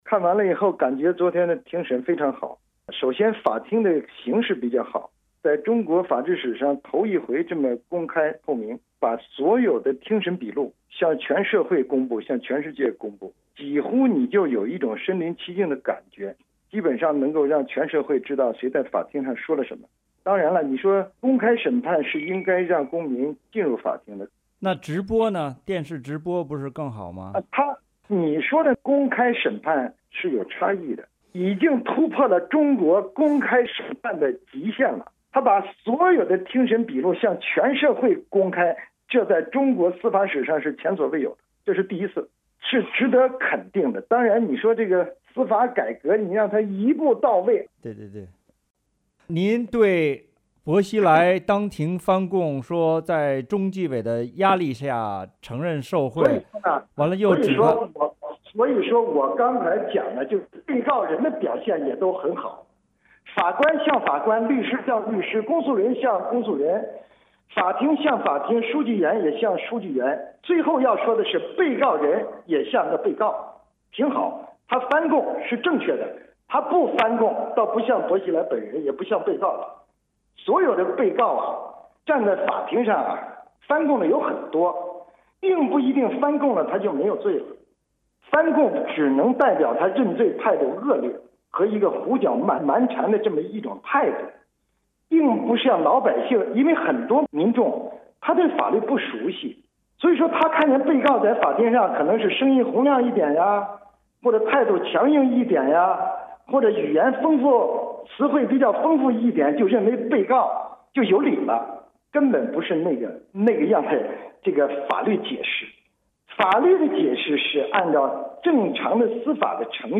2009年12月被重庆当局以“伪造证据罪”遭判监1年半的原北京康达律师事务所知名律师李庄，星期五接受美国之音专访，点评薄熙来案第一天庭审的情况。